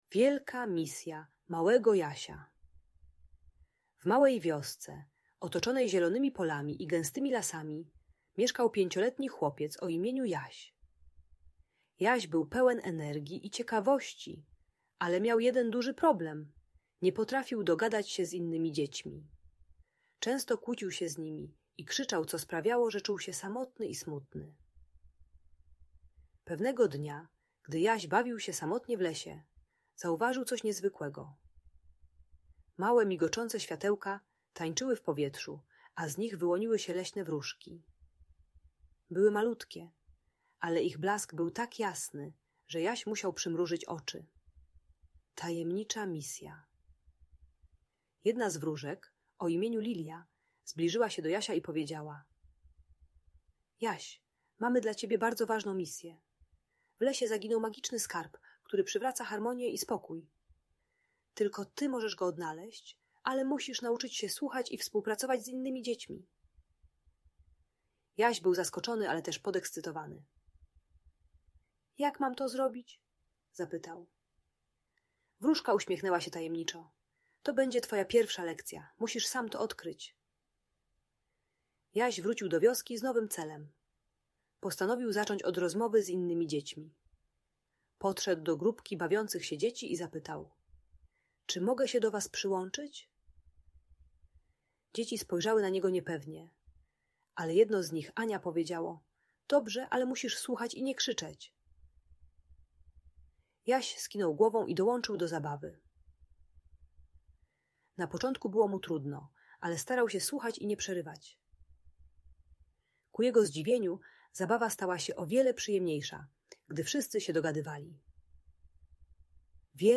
Wielka misja małego Jasia - inspirująca opowieść - Audiobajka